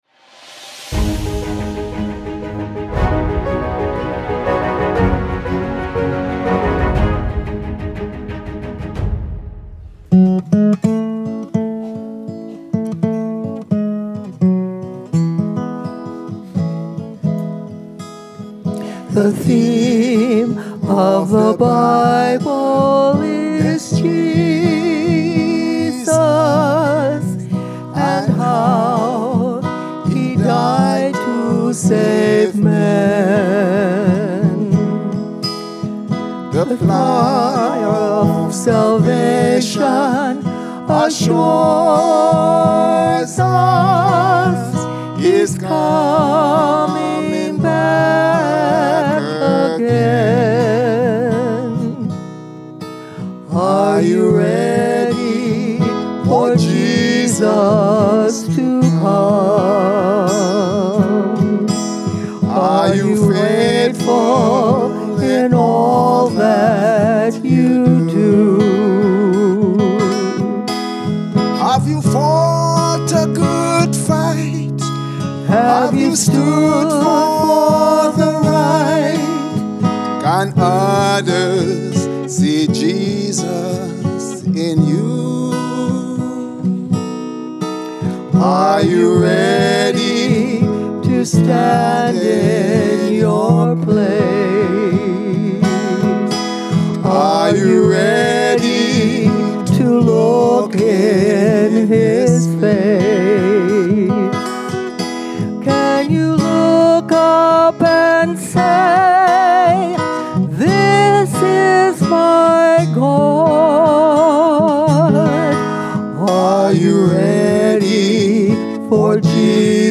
From Series: "Central Sermons"